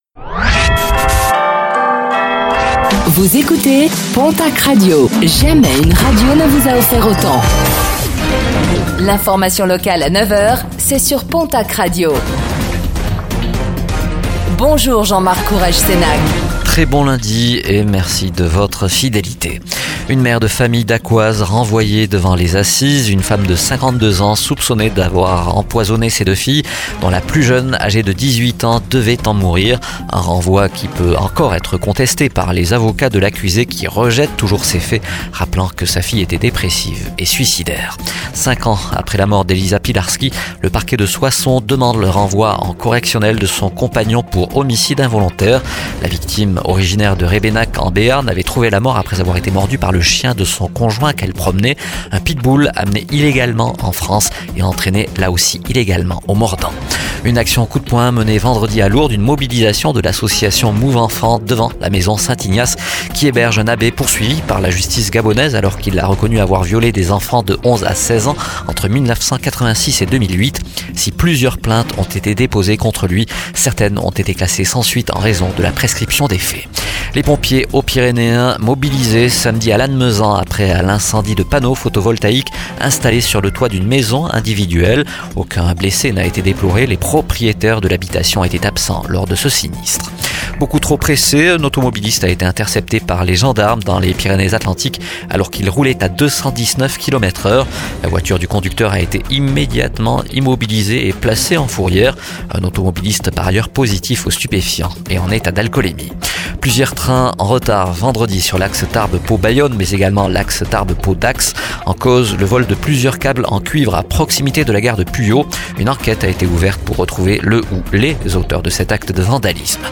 09:05 Écouter le podcast Télécharger le podcast Réécoutez le flash d'information locale de ce lundi 27 mai 2024